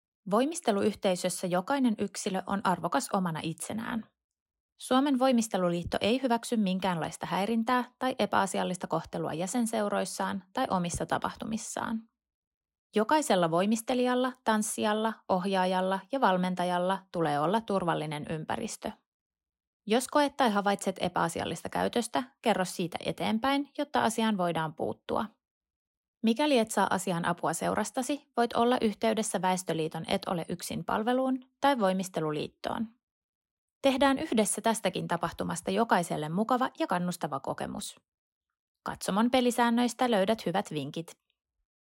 Kuulutus tapahtumiin ja kilpailuihin: aiheena vastuullinen voimistelu.
aanimainos_vastuullisuuswav.mp3